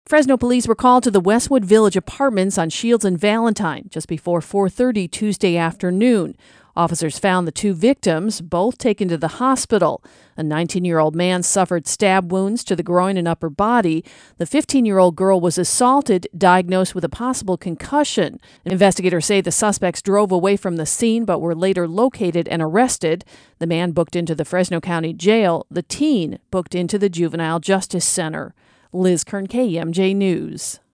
report